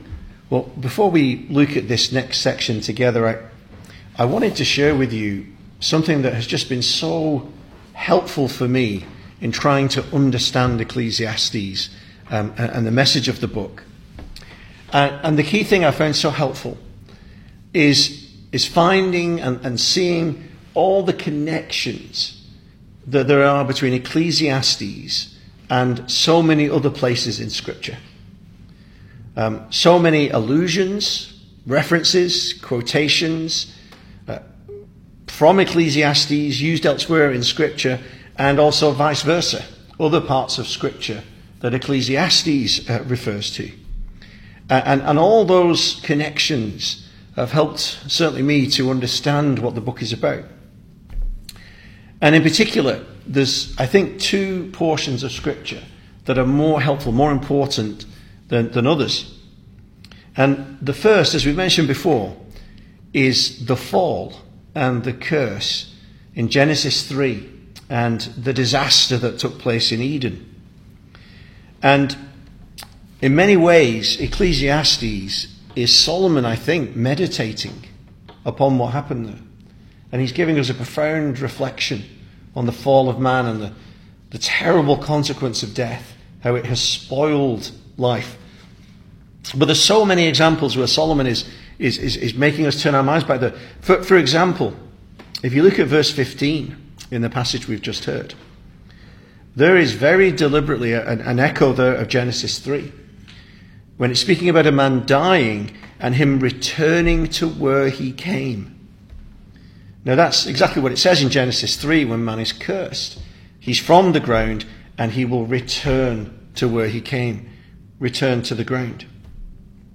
2026 Service Type: Weekday Evening Speaker